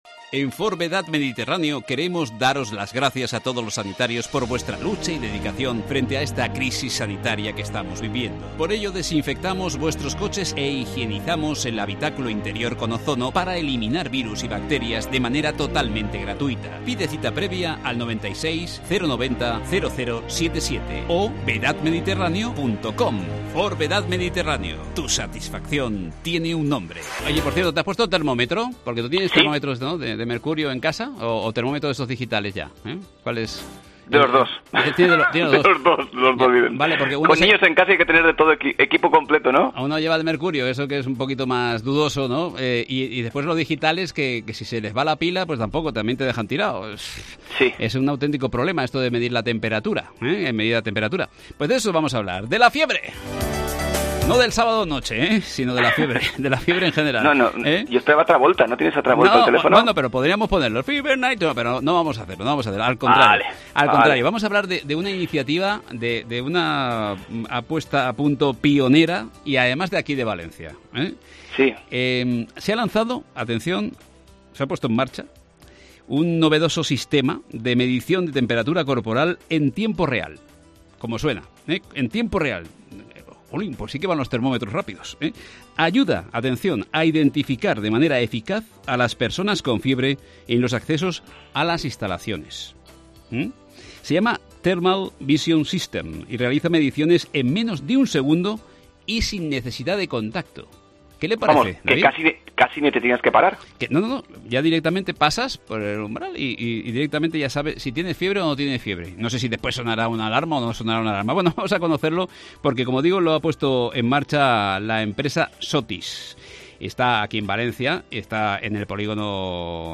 Redacción digital Madrid - Publicado el 21 may 2020, 17:11 - Actualizado 16 mar 2023, 23:21 2 min lectura Descargar Facebook Twitter Whatsapp Telegram Enviar por email Copiar enlace Entrevista LUZ DE CRUCE. El sistema Thermal Vision System ayuda a identificar de manera eficaz a las personas con fiebre en los accesos a instalaciones.